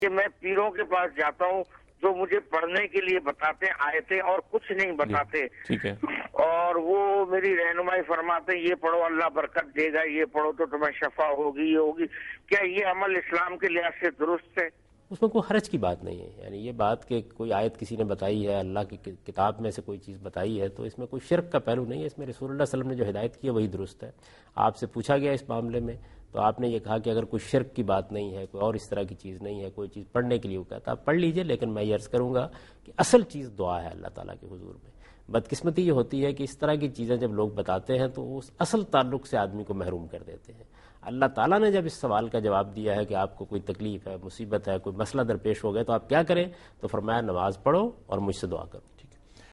Answer to a Question by Javed Ahmad Ghamidi during a talk show "Deen o Danish" on Duny News TV
دنیا نیوز کے پروگرام دین و دانش میں جاوید احمد غامدی ”آیات کا ورد “ سے متعلق ایک سوال کا جواب دے رہے ہیں